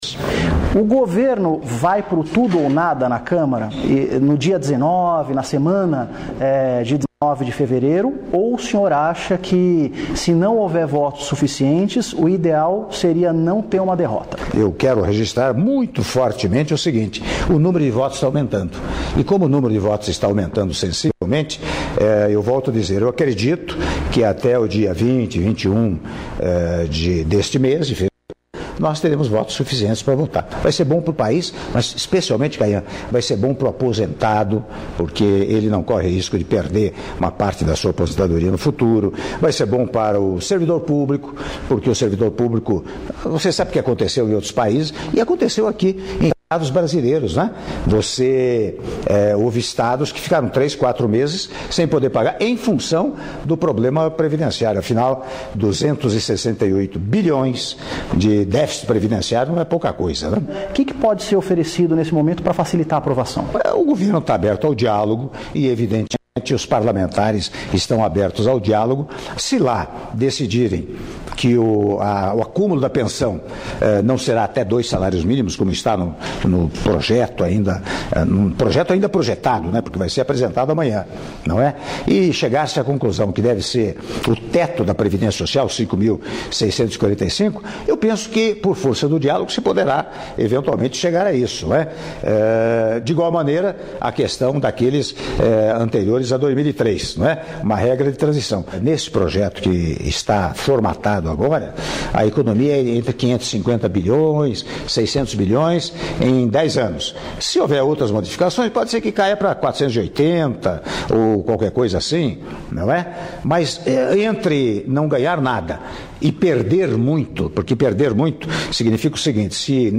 Áudio da entrevista exclusiva concedida pelo Presidente da República, Michel Temer, ao Jornal da Band - (04min44s)